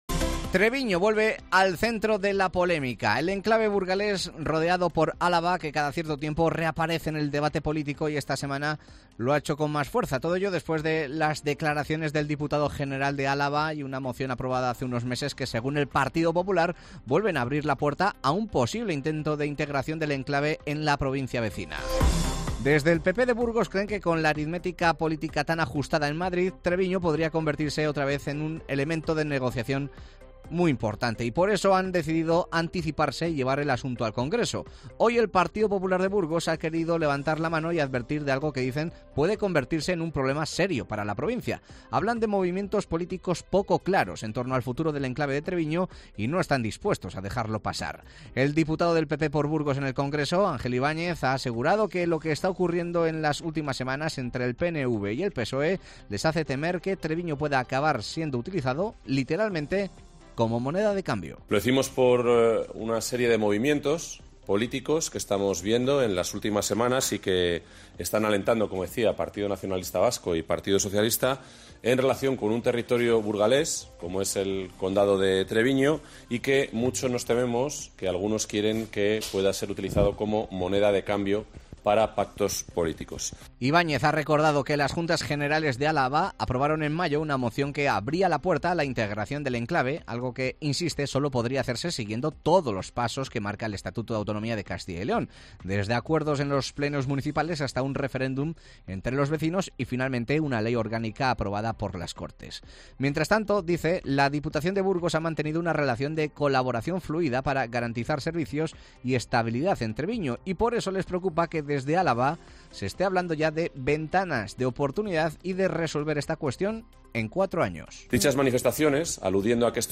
Los parlamentarios nacionales del PP de Burgos, Ángel Ibáñez y Javier Lacalle, dan a conocer las iniciativas que se debatirán esta semana en el Congreso de los Diputados en relación con el Condado de Treviño